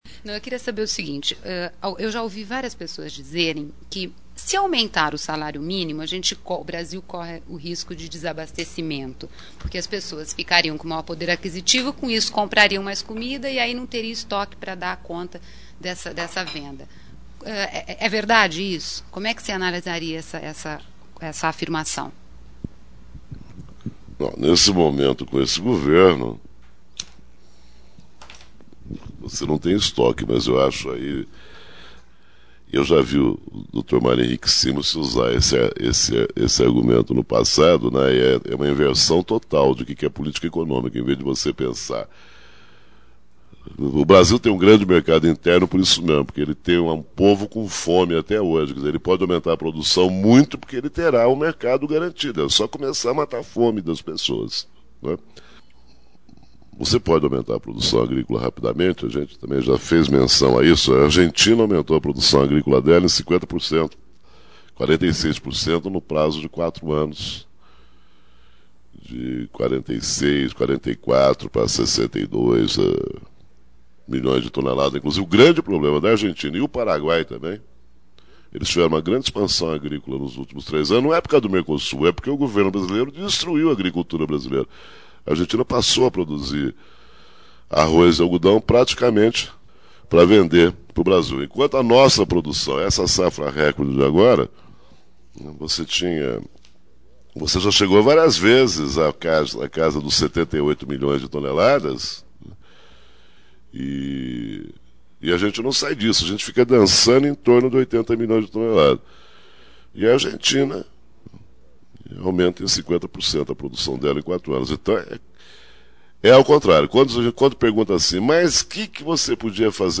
Produção versus consumo Repórter do Futuro, na Oboré Gravado em São Paulo, 27/11/1999